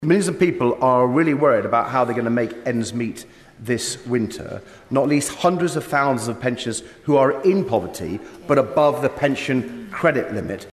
DAVEY-WINTER-FUEL-PMQS.mp3